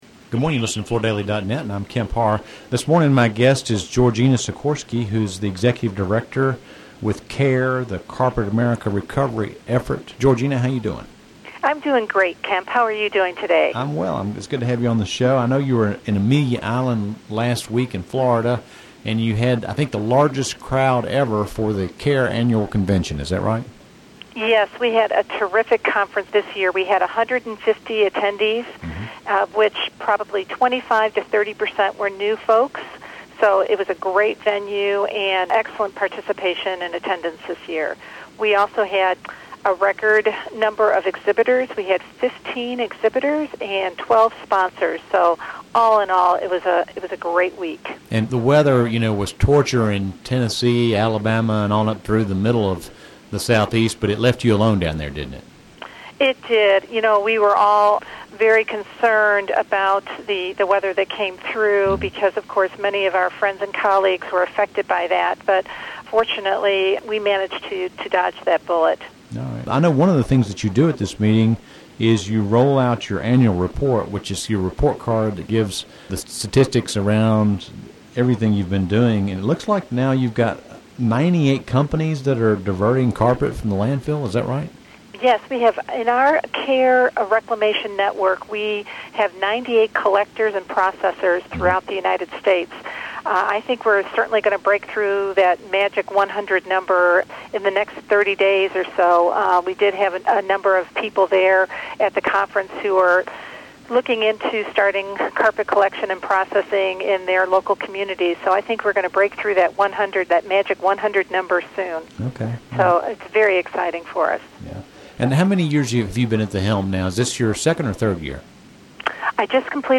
Listen to the interview to hear a quick review of how CARE is doing on its mission to divert post-consumer carpet from landfills.